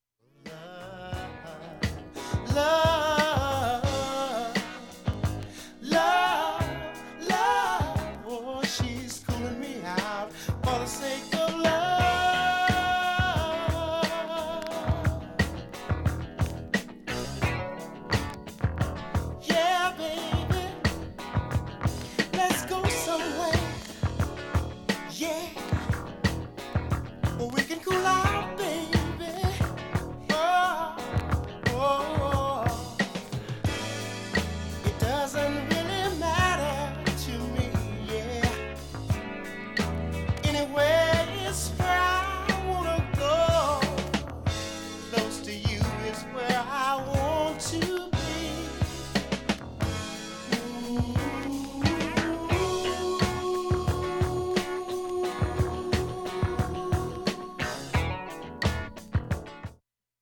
盤面きれいで音質良好全曲試聴済み。
周回プツ出ますがかすかです。
音質目安にどうぞ
ほか３回までのかすかなプツが２箇所
◆ＵＳＡ盤オリジナル